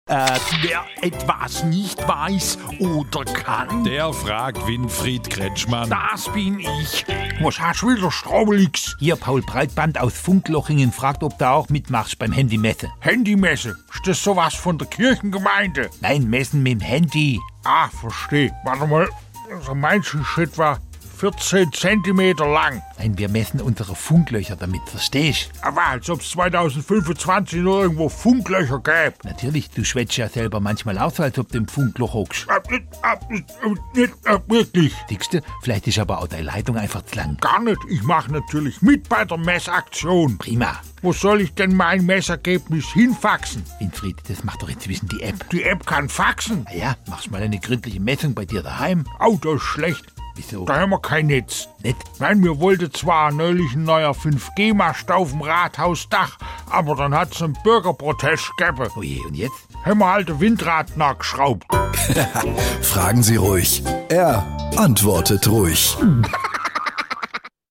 SWR3 Comedy Fragen Sie Kretschmann: Breitbandmessung